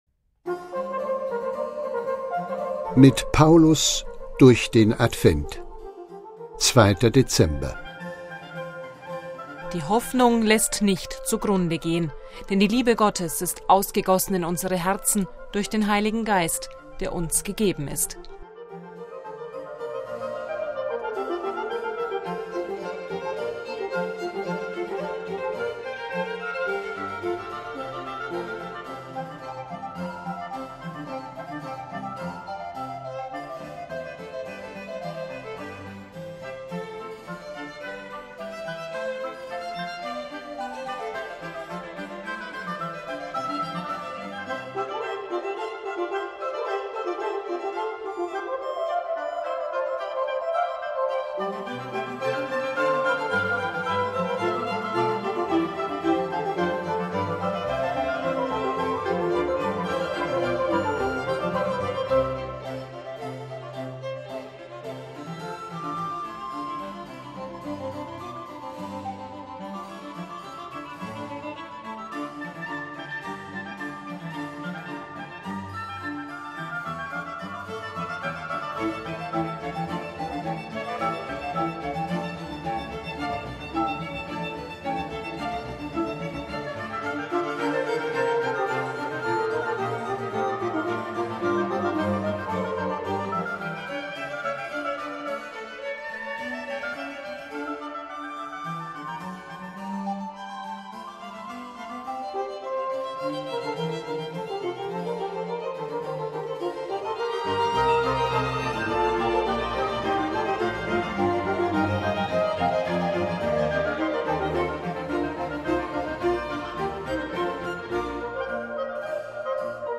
„Mit Paulus durch den Advent“ ist das Motto dieses Audio-Adventskalenders, und an 24 Tagen lesen die Mitarbeiterinnen und Mitarbeiter einen ausgewählten Satz aus den Paulusbriefen.